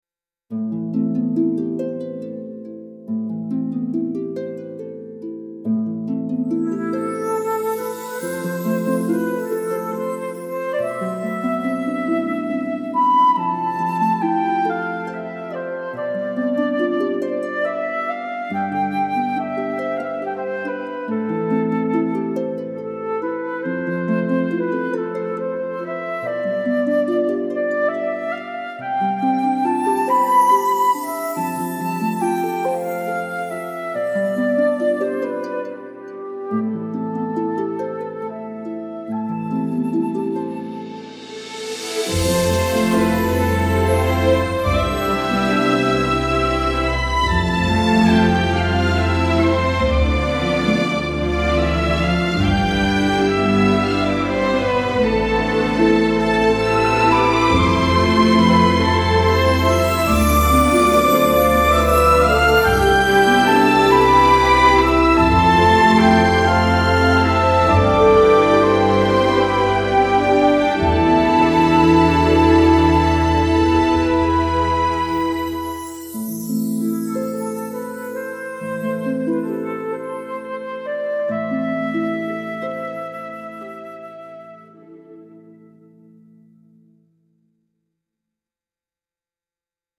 polished orchestral waltz with harp and flute, refined and beautiful